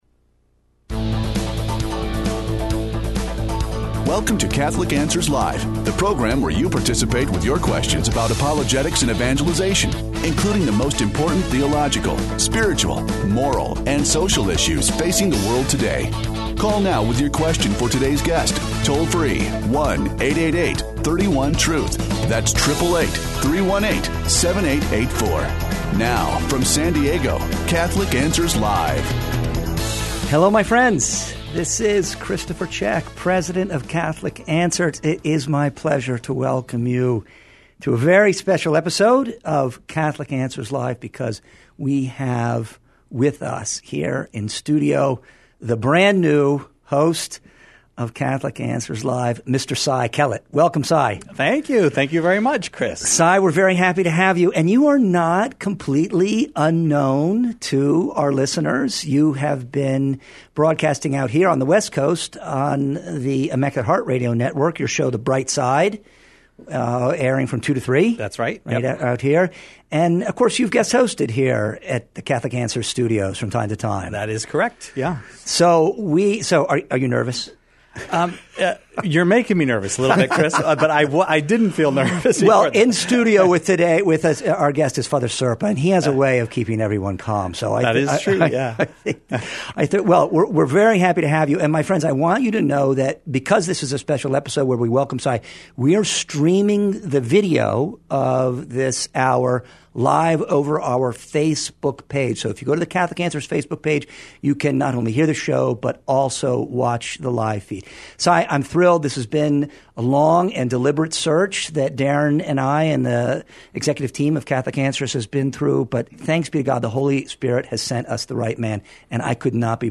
takes questions of a pastoral nature in this hour devoted to the care of souls, growth in the spiritual life, and healthy relationships.